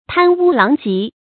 贪污狼藉 tān wū láng jiè 成语解释 谓贪财纳贿，行为不检，声名败坏。